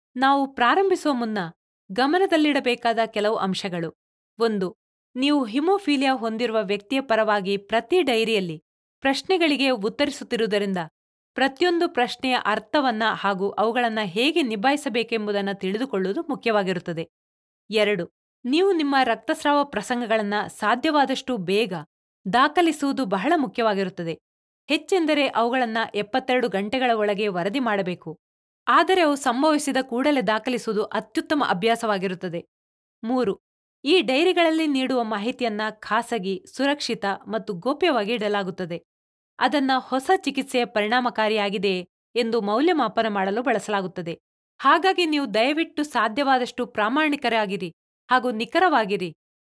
Kannada Voice Artist Samples
Kannada Female Voice Over Artist